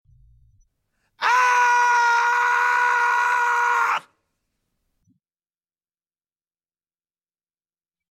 Man Angry Loud Hd Sound Button - Free Download & Play